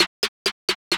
Antidote Snare Roll.wav